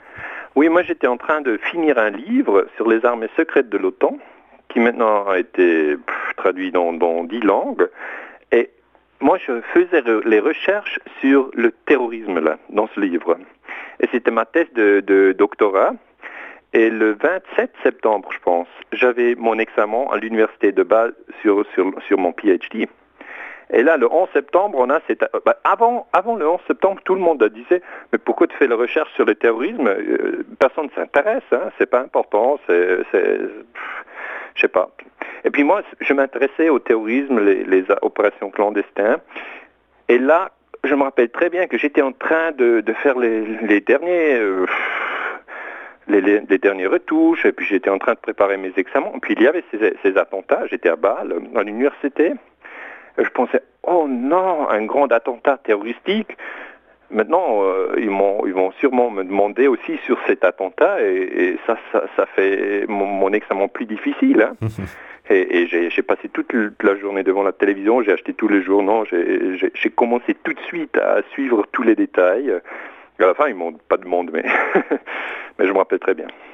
Daniele Ganser, historien